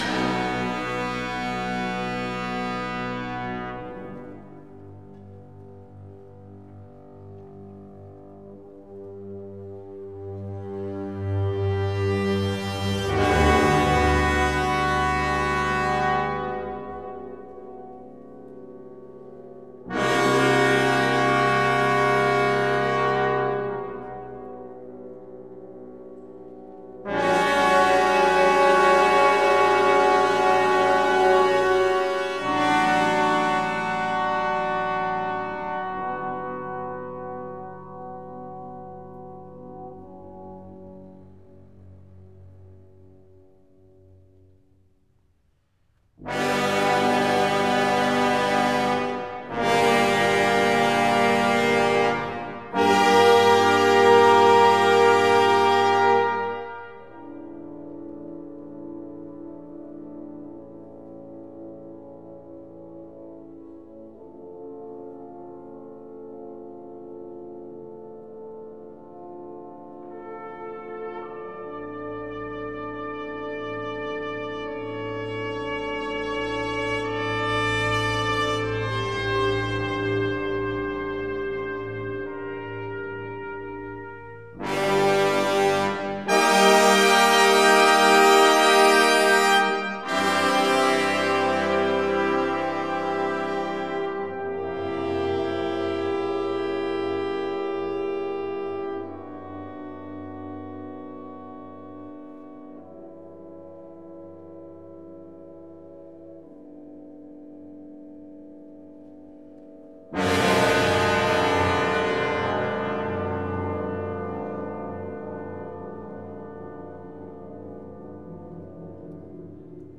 Ein Jahr später kam ein Komponist mit Namen Maurice Ravel zur Welt und fand viele Jahre später, dass die „Bilder einer Ausstellung" in einer Orchesterversion doch wesentlich ausdrucksvoller und einprägsamer seien. 1922 setzte er sich hin und bearbeitete das Werk für Orchester.